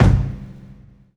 Kick (16).wav